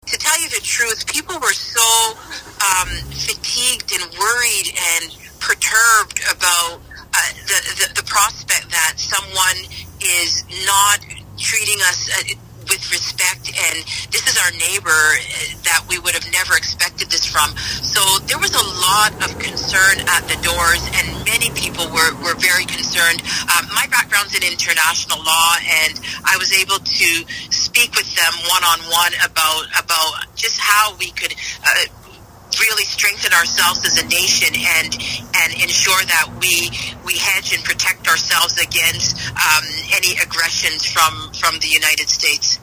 Speaking with the media over the phone, she was thrilled to be given the chance to work for the people of the riding for four more years.